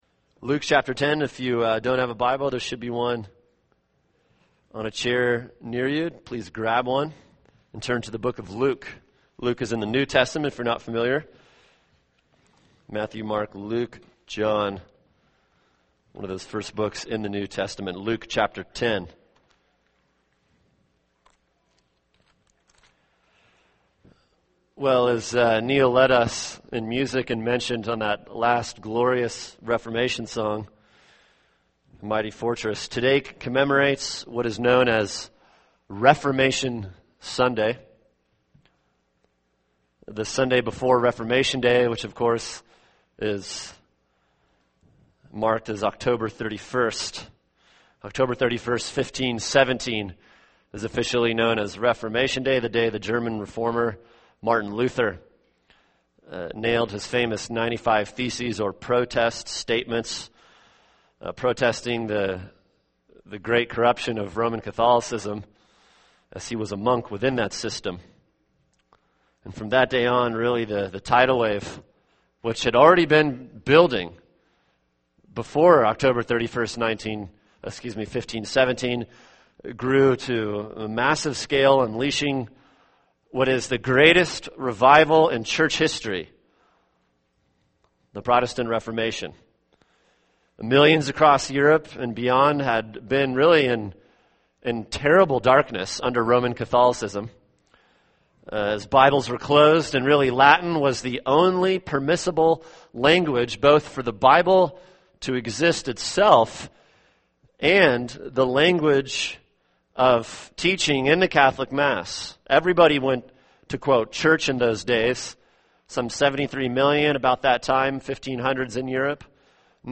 [sermon] Luke 10:38-42 “The One Thing” | Cornerstone Church - Jackson Hole